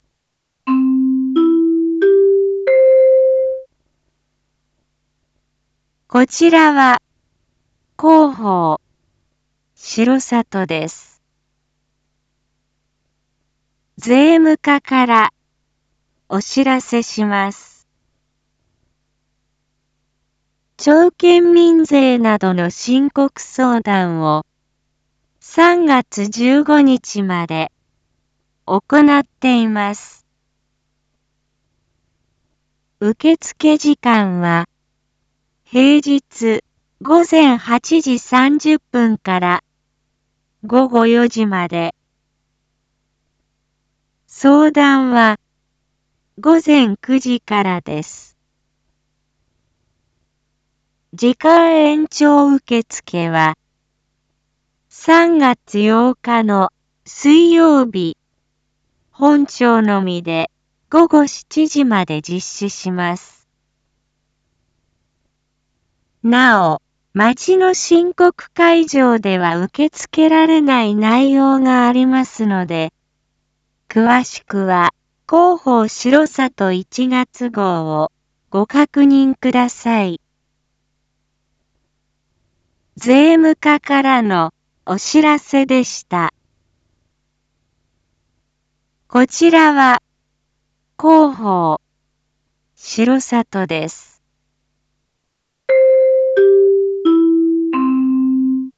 一般放送情報
Back Home 一般放送情報 音声放送 再生 一般放送情報 登録日時：2023-03-06 07:01:41 タイトル：R5.3.6 7時放送分 インフォメーション：こちらは広報しろさとです。